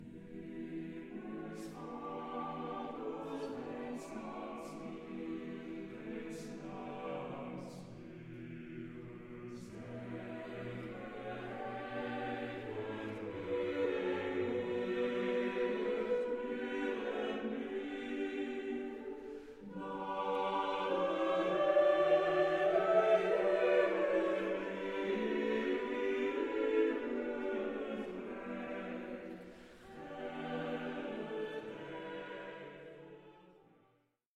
SATB (4 voces Coro mixto) ; Partitura general.
Sagrado. Motete. contemporáneo. Plegaria.